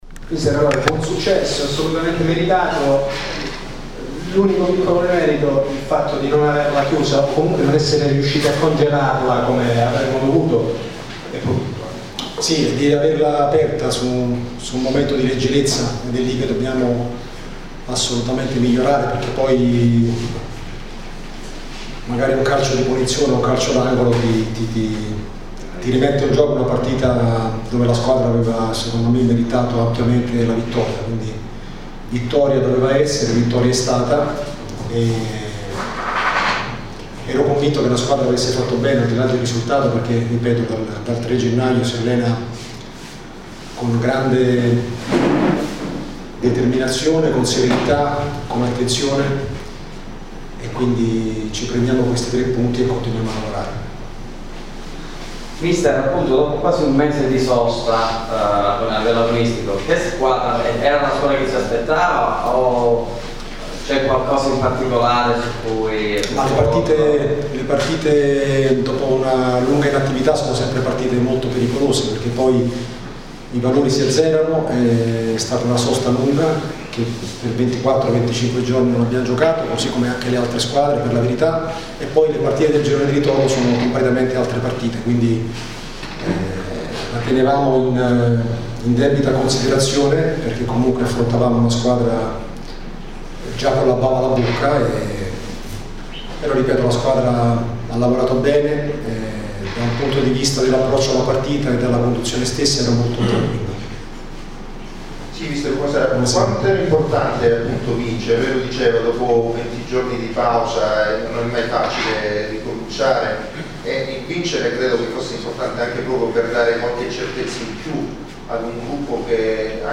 Ecco le interviste audio a fine partita: